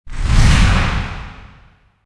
PixelPerfectionCE/assets/minecraft/sounds/mob/enderdragon/wings2.ogg at mc116
wings2.ogg